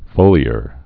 (fōlē-ər)